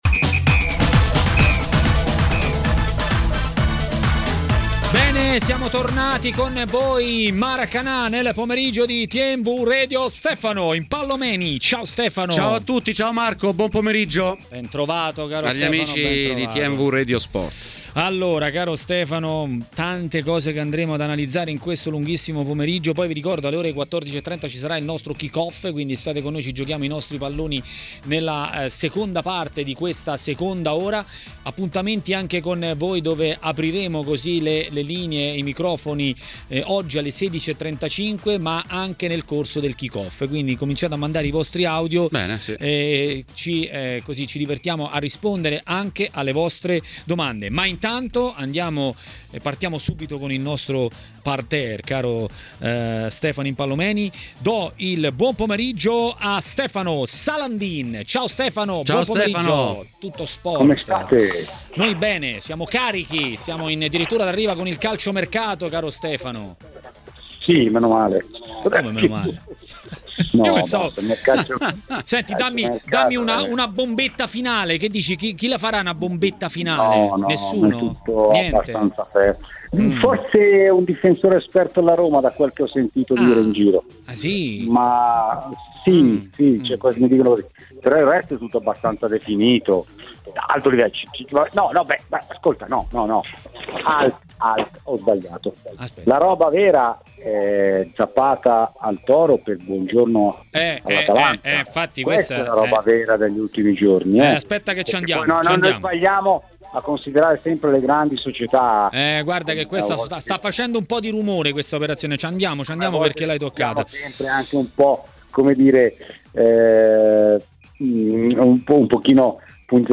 ha parlato ai microfoni di TMW Radio, durante Maracanà